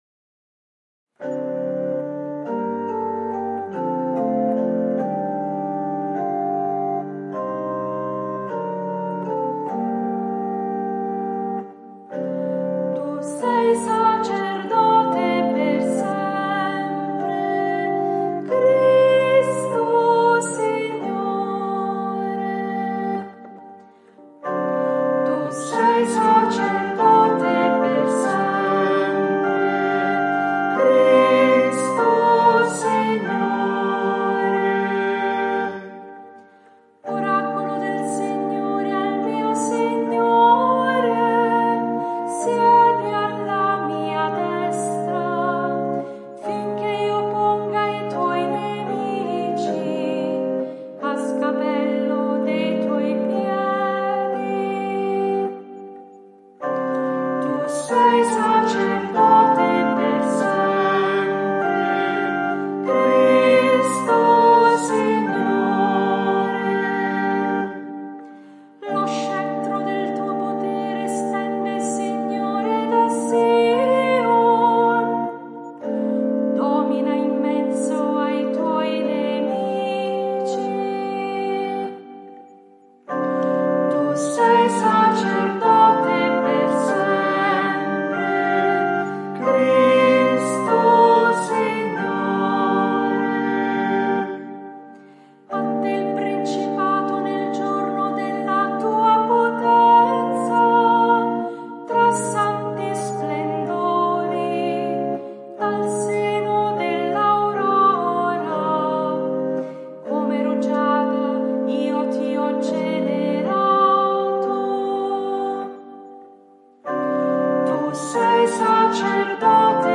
SALMO RESPONSORIALE